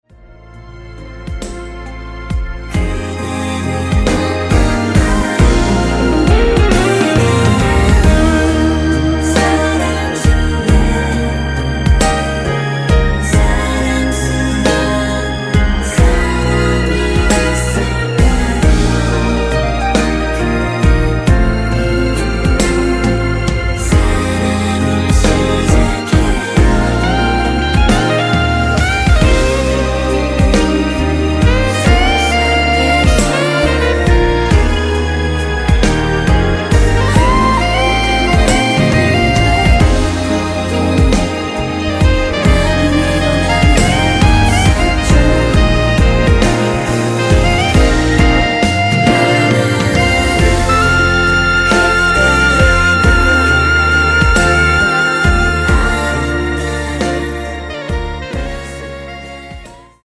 순수 코러스만 들어가 있으며 멤버들끼리 주고 받는 부분은 코러스가 아니라서 없습니다.(미리듣기 확인)
원키에서(+4)올린 코러스 포함된 MR입니다.
Eb
앞부분30초, 뒷부분30초씩 편집해서 올려 드리고 있습니다.
중간에 음이 끈어지고 다시 나오는 이유는